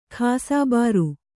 ♪ khāsābāru